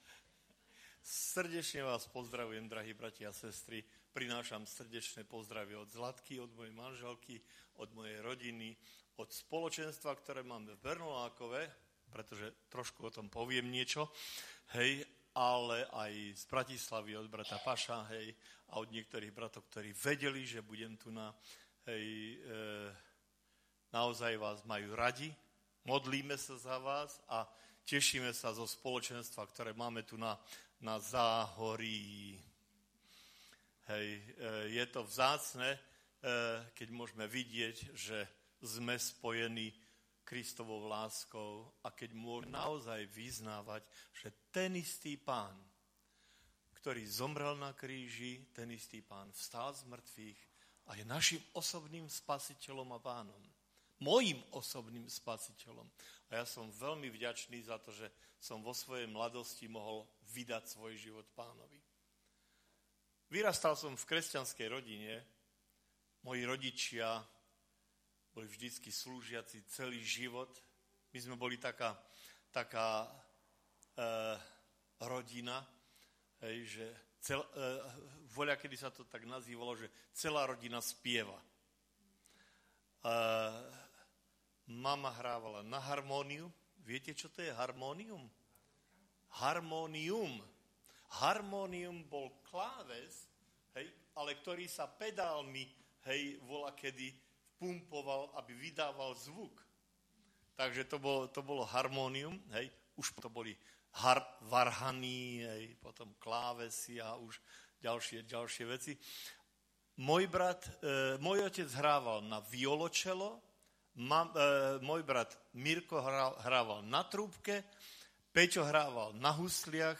Vydané: 2019 Žáner: kázeň